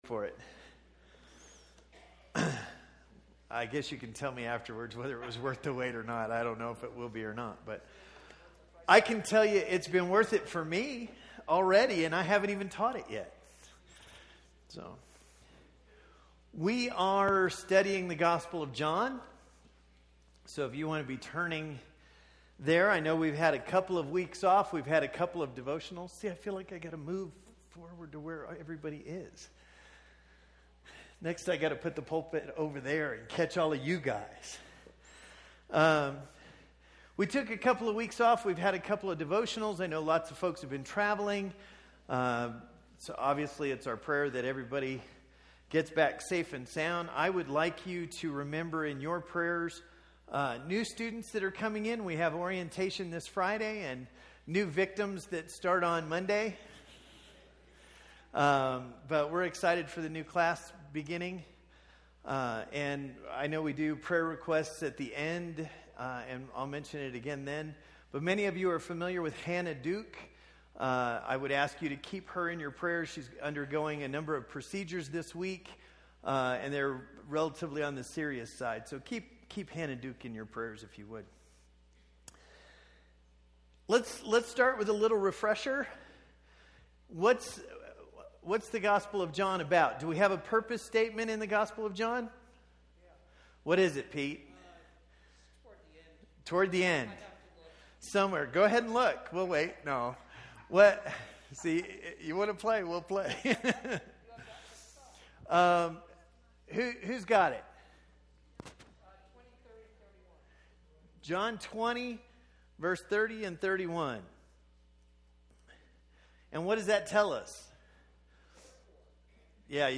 This is a study of the I AM statements of Jesus in the Gospel of John. Tonight's lesson comes from John 6. These presentations are part of the Wednesday night adult Bible classes at the Bear Valley church of Christ.